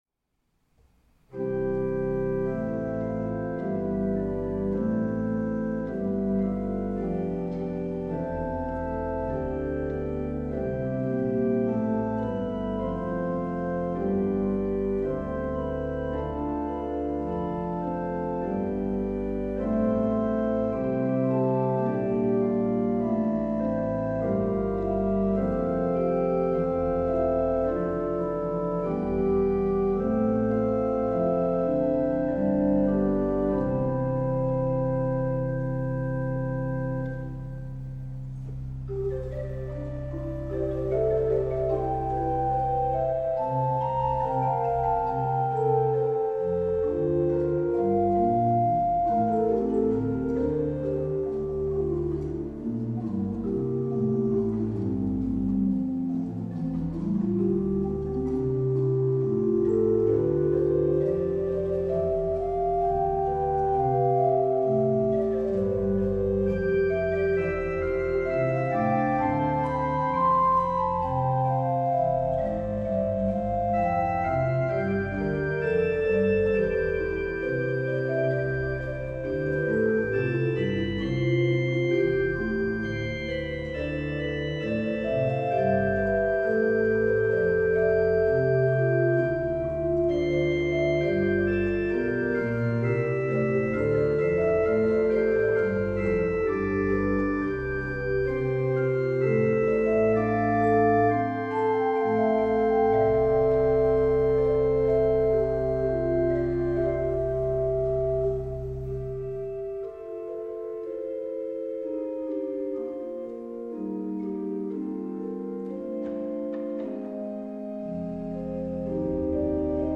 Improvisation über das Lied „Selig, wem Christus auf dem Weg begegnet“ (Gotteslob 275)